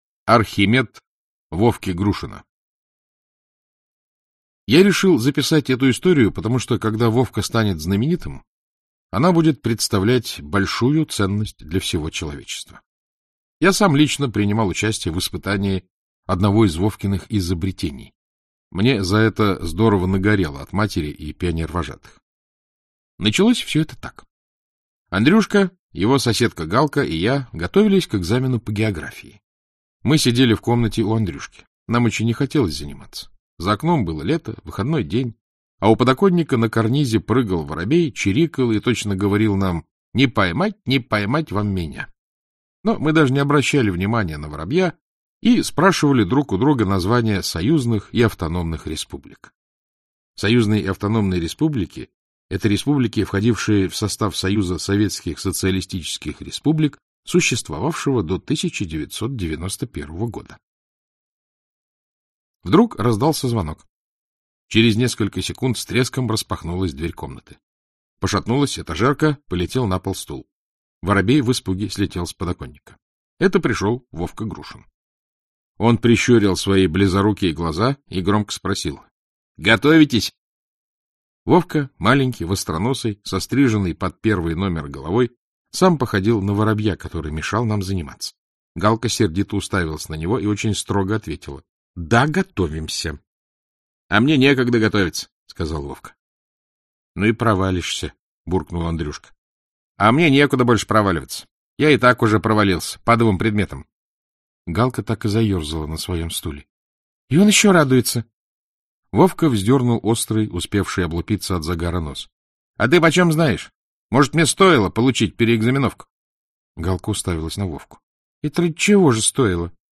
Aудиокнига «Архимед» Вовки Грушина Автор Юрий Сотник Читает аудиокнигу Александр Клюквин.